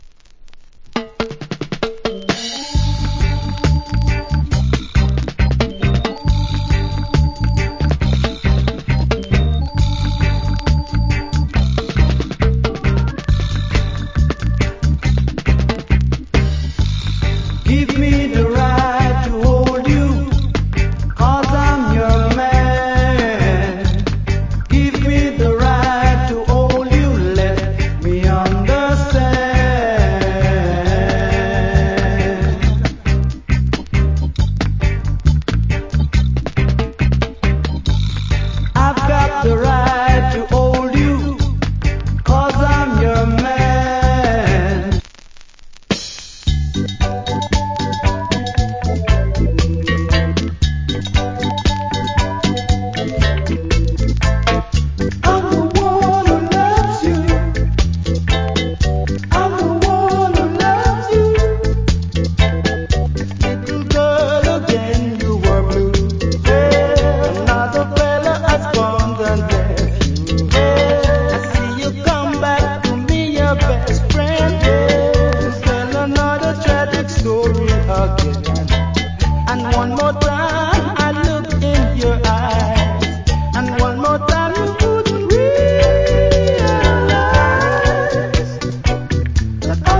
Cool Reggae Vocal + Dub.